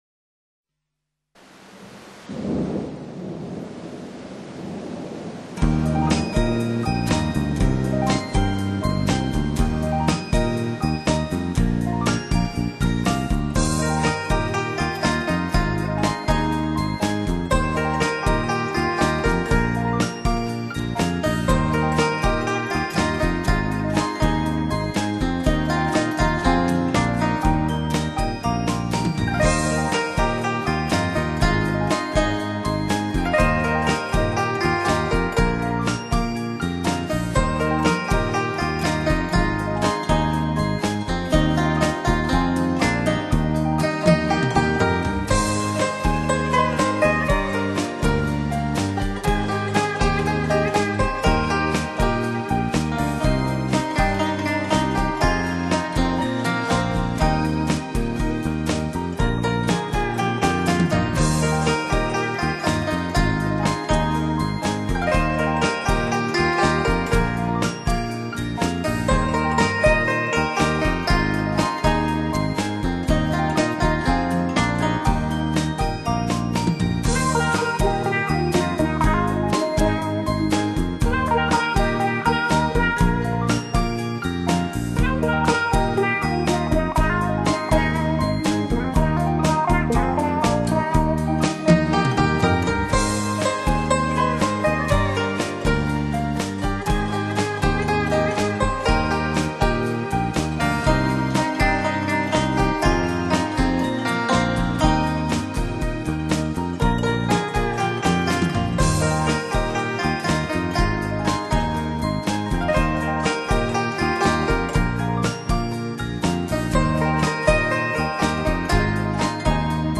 古筝，弹弦乐器。
特有动人心弦而又激昂震撼之音色，演译不朽经典旋律，予人万马千军感受...